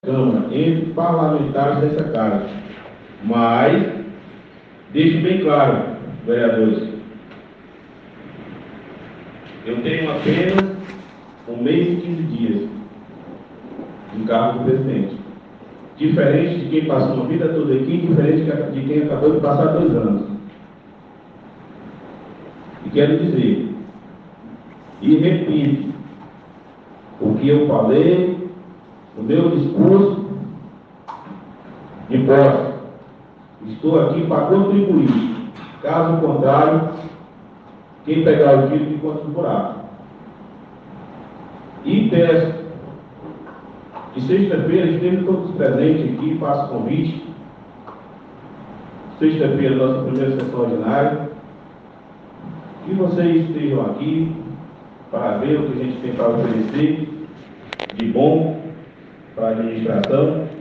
A gestão do presidente da Mesa Diretora da Câmara Municipal de Envira (AM), vereador Rômulo Oliveira, popular Morzim, começa com polêmicas preocupantes, criadas por ele mesmo, que no seu discurso de posse fez duras críticas aos gestores anteriores do Poder Legislativo municipal e em tom ameaçador enfatizou que está no cargo de presidente para contribuir, mas em seguida se dirigiu aos colegas parlamentares em tom ameaçador.
Ouça o áudi0 do discurso do presidente com séria ameaça aos seus pares